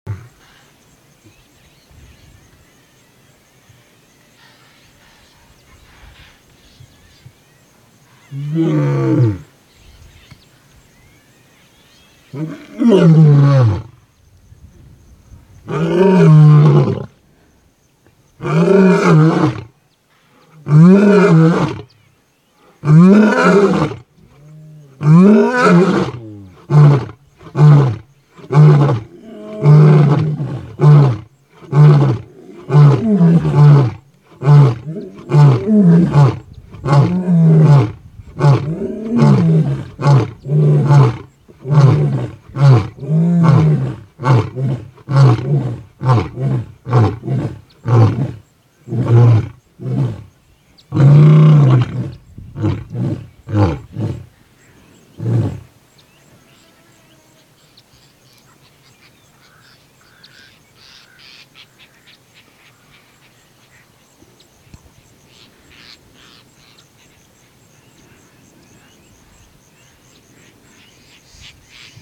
Lion roar
lion-call-1.mp3